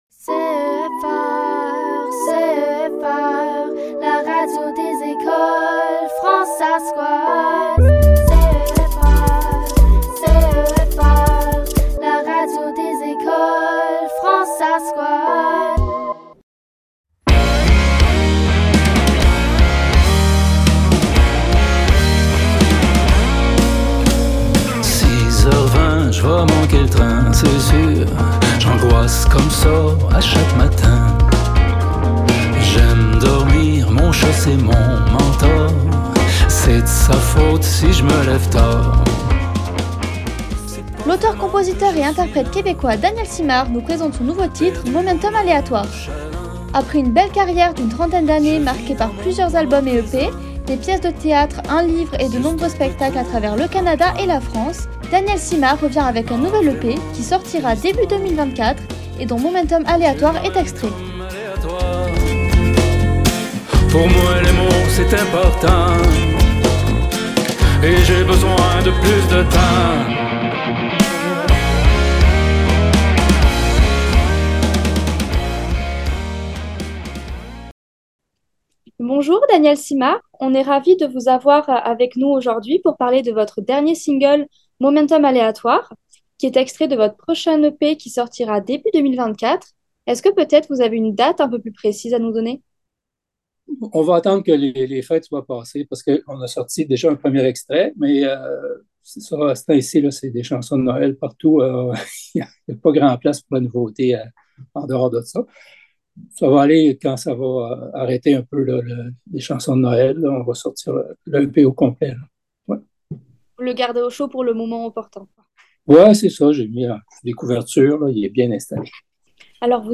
Rencontre exclusive